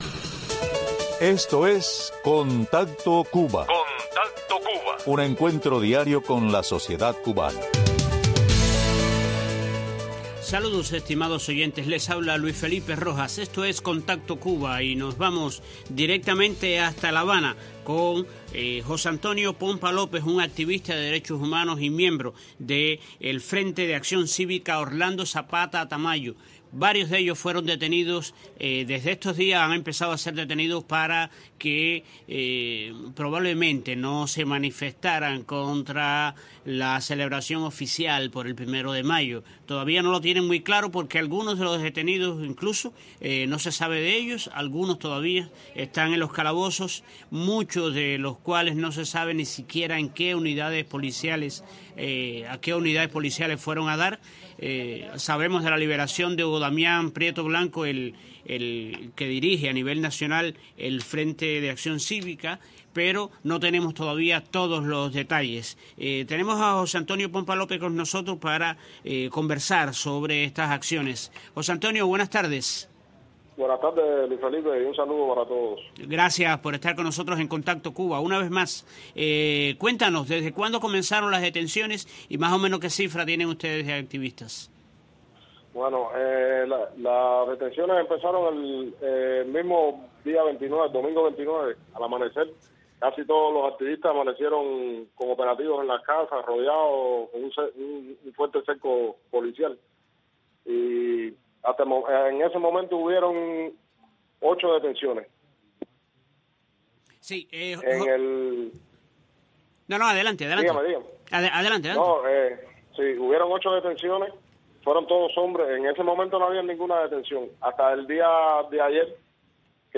Este programa se empeña en darles voz a los hombres y mujeres que hacen la Cuba del futuro, el país que buscamos hoy. Temas sociales, económicos y la agenda que la sociedad civil independiente comparte con la comunidad más cercana.